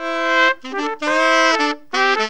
HORN RIFF 1.wav